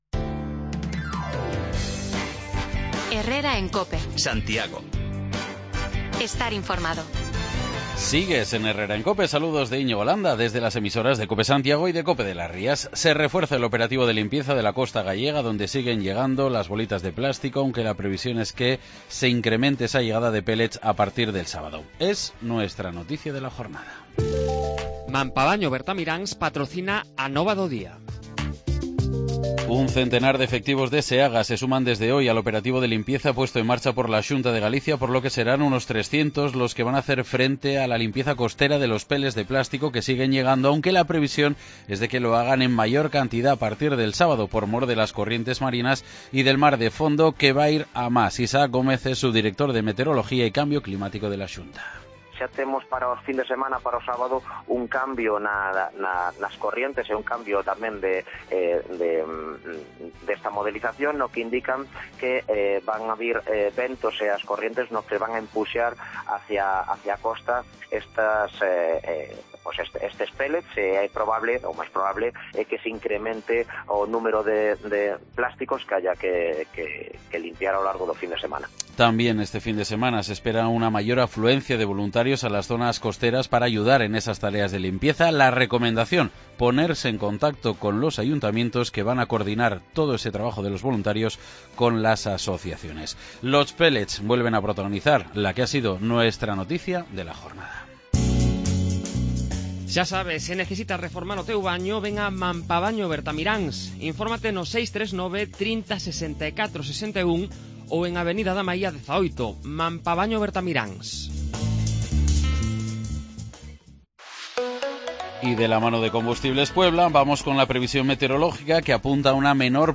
Nos acercamos hasta el Clínico de Santiago para saber cómo acogen los pacientes la obligación de emplear mascarillas. Nos encontramos unanimidad en este asunto... y también en la queja por la falta de una solución al problema de aparcamiento en el Hospital de referencia para la capital de Galicia y las comarcas del entorno